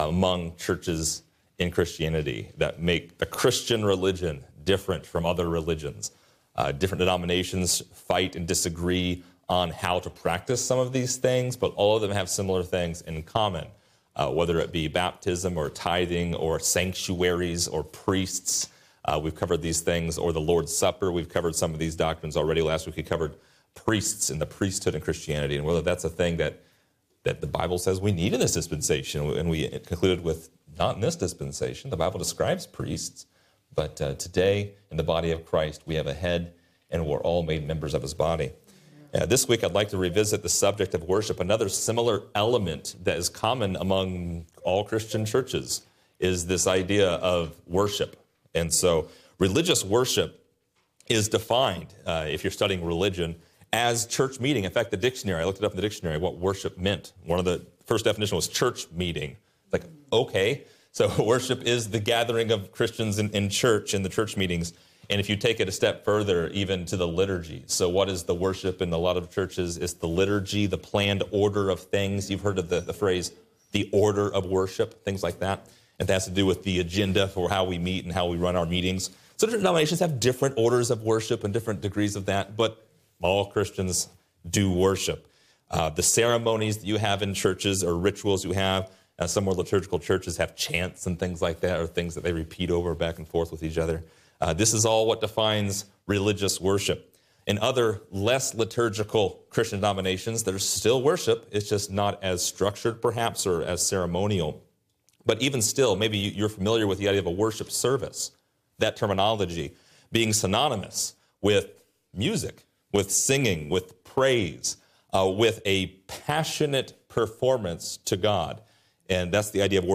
What exactly is worship? Find out in this lesson!